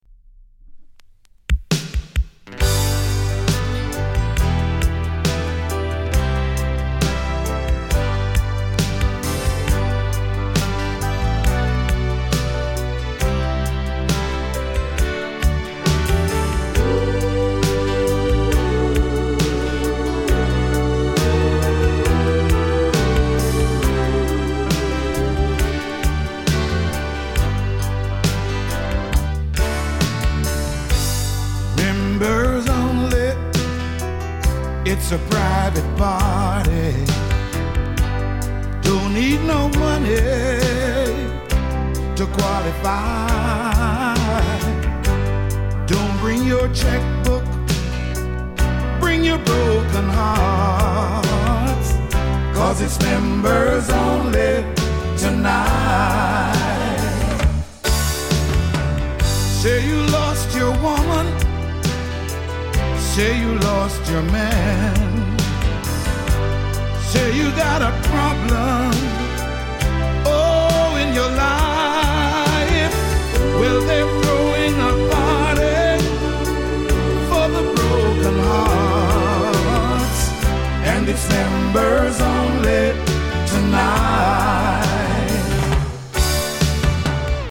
SOUL作品